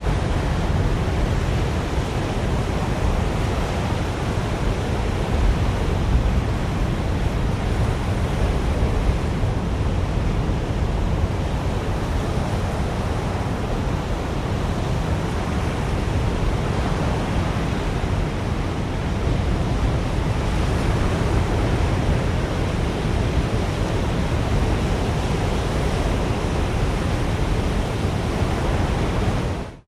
am_hurricane_02_hpx
Hurricane force winds blow steadily. Winds, Hurricane Weather, Hurricane Storm, Hurricane